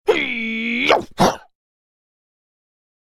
Download Karate sound effect for free.
Karate